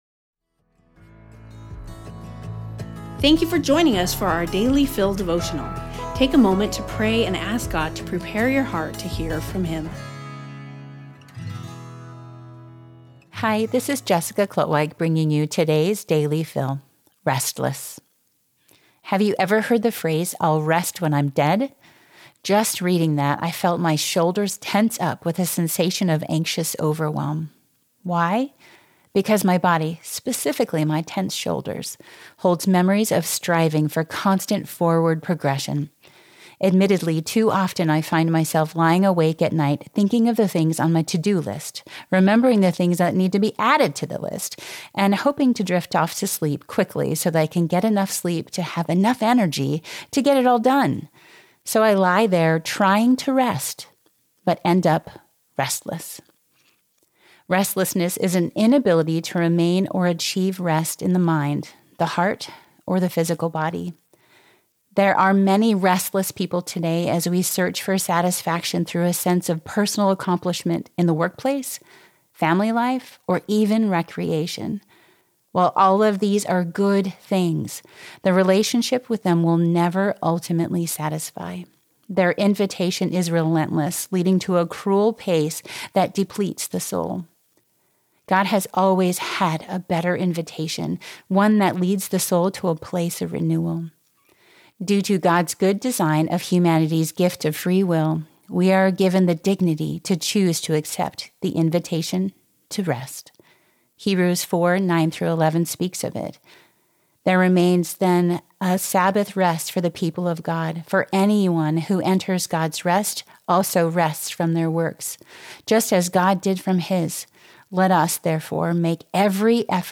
We hope you will enjoy these audio devotionals.